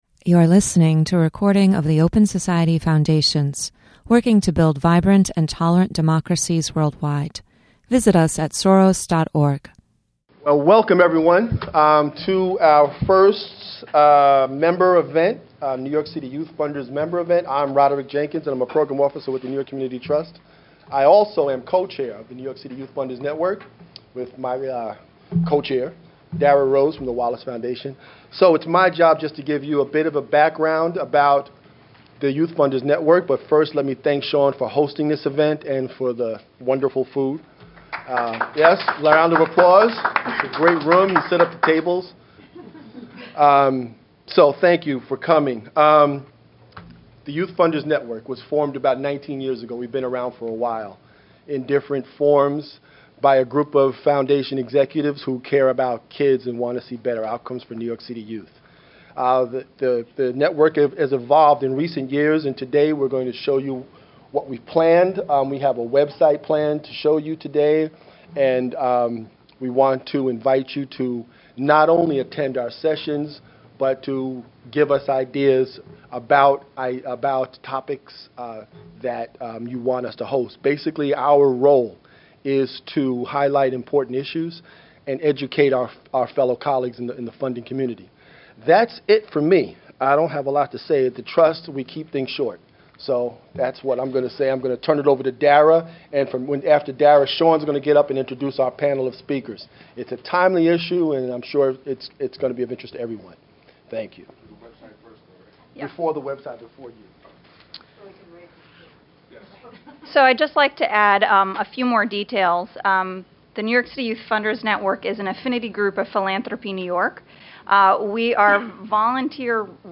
New York City Youth Funders Panel Discussion
Various representatives from the philanthropic community discuss the importance of public/private partnership and the need for targeted investments and strategies for youth of color.
New York City Youth Funders Panel Discussion (June 15, 2011) Download MP3 The New York City Youth Funders Network held its inagural meeting at the Open Society Foundations New York offices.